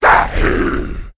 c_saurok_hit3.wav